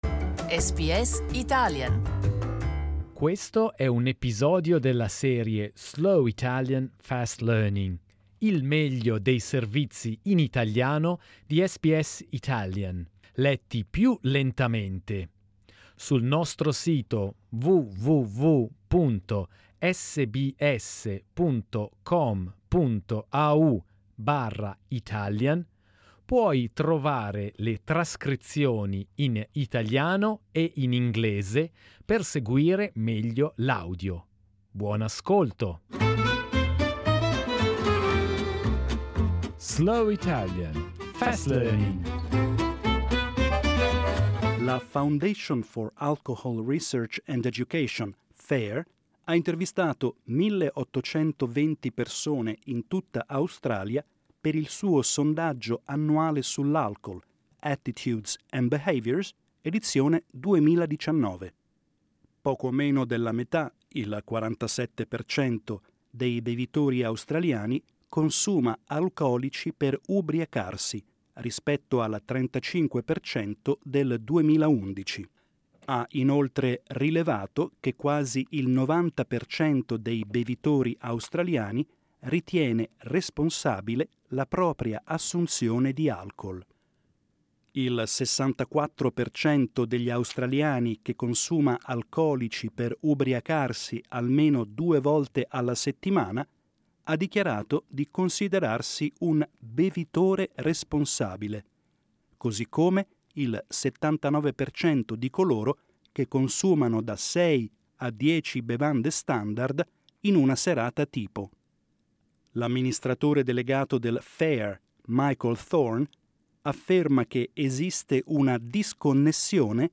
SBS Italian news, with a slower pace. This is Slow Italian, Fast Learning, the very best of the week’s news, read at a slower pace , with Italian and English text available .